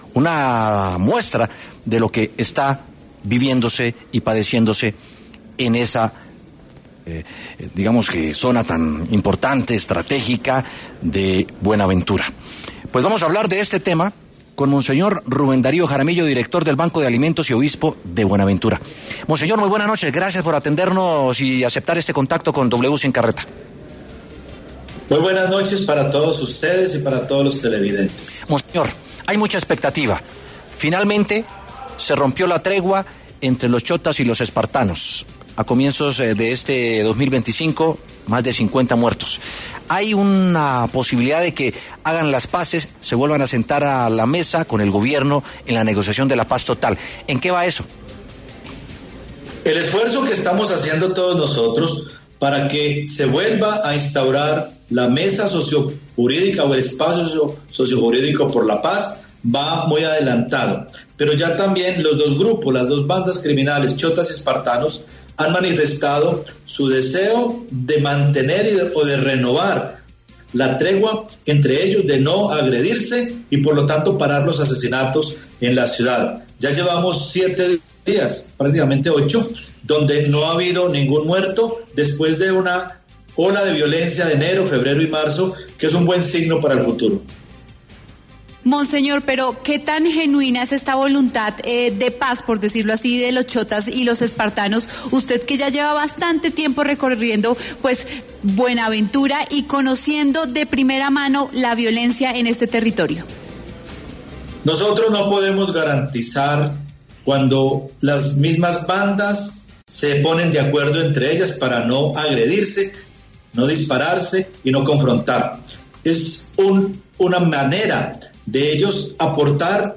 Monseñor Rubén Darío Jaramillo, director del Banco de Alimentos y obispo de Buenaventura, pasó por los micrófonos de W Sin Carreta para hablar sobre la ruptura de la tregua y la guerra entre las bandas los ‘Shottas’ y los ‘Espartanos’.